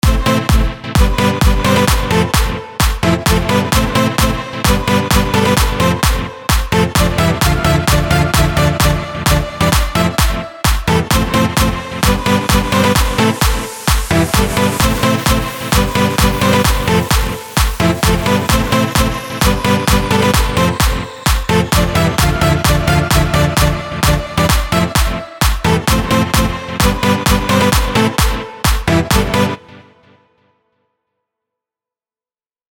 טראקים שבניתי בFL סטודיו